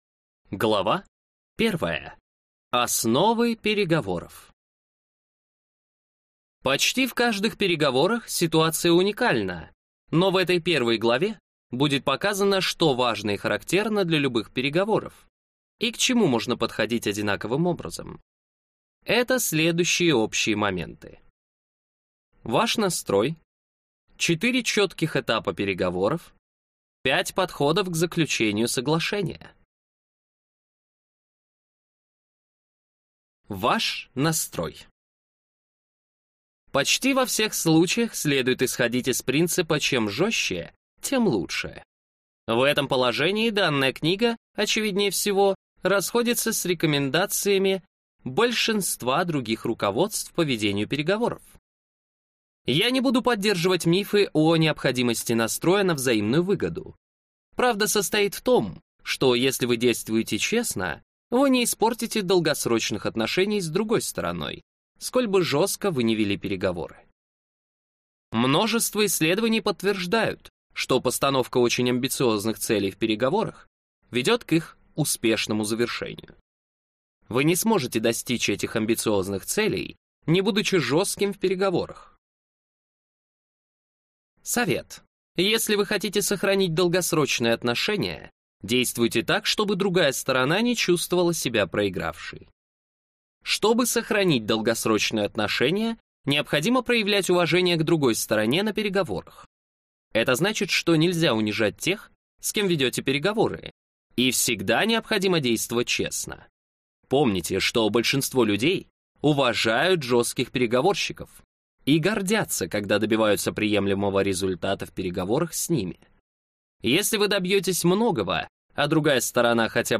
Аудиокнига Искусство переговоров. Что лучшие переговорщики знают, делают и говорят | Библиотека аудиокниг